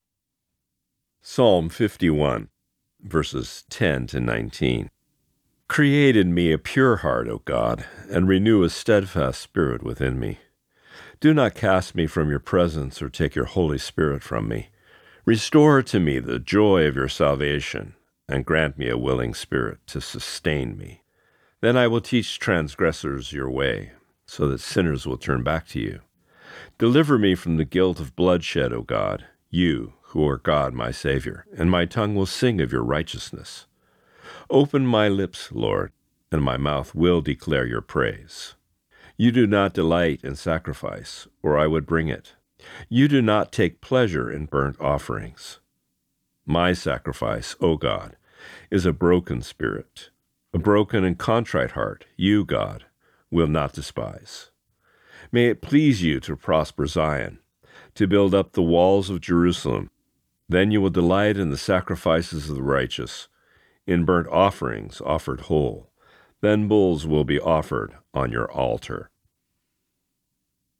Reading: Psalm 51:10-19